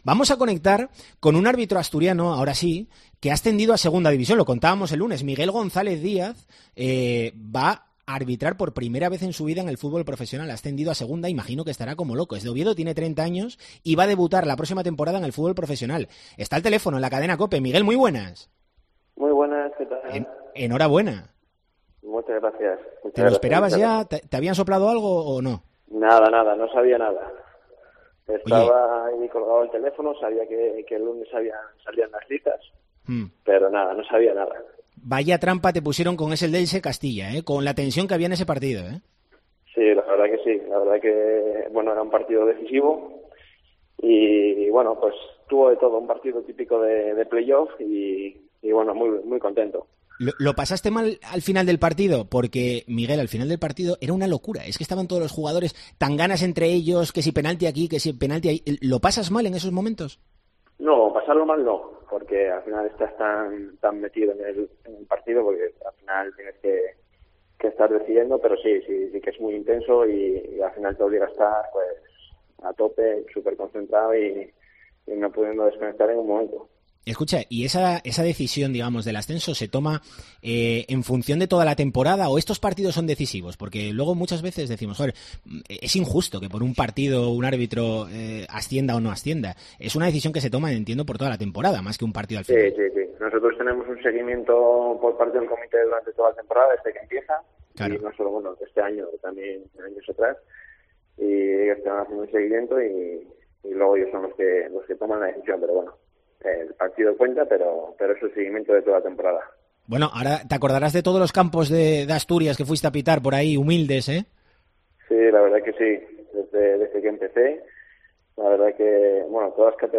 ENTREVISTA DCA